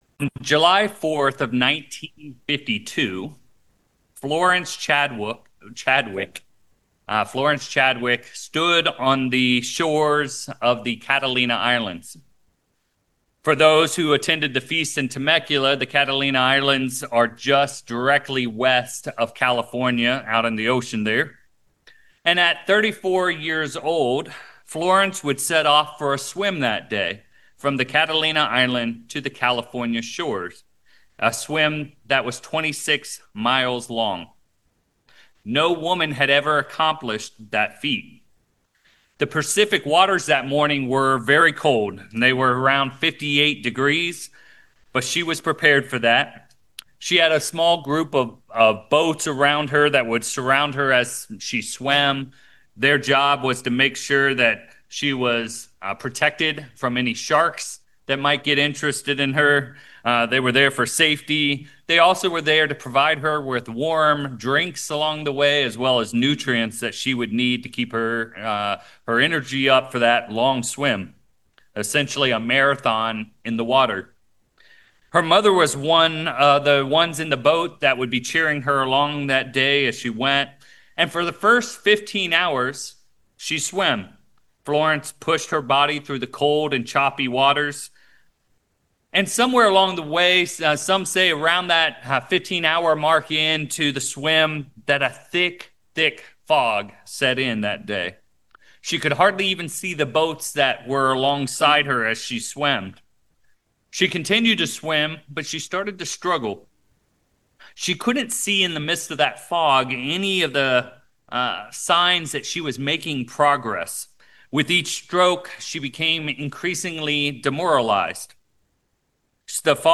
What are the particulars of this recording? Given in San Francisco Bay Area, CA San Jose, CA